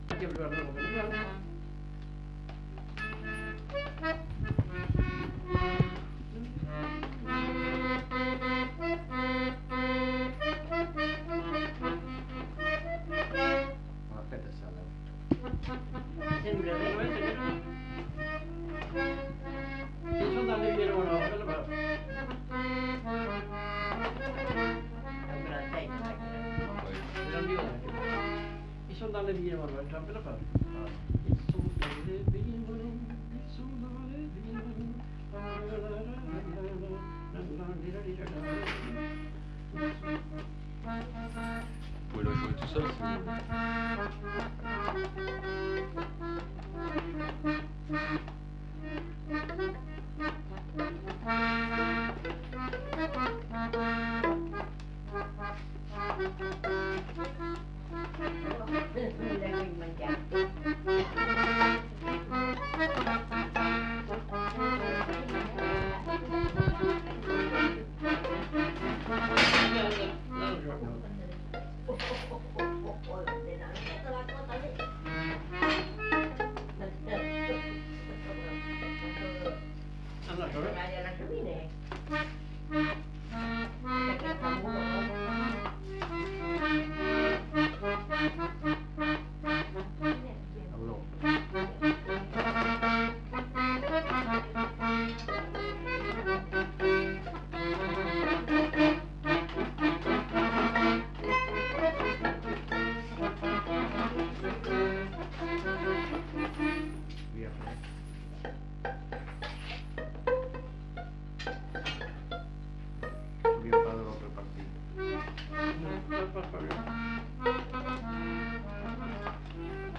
Répertoire de danses des Petites-Landes interprété au violon et à l'accordéon chromatique
Scottish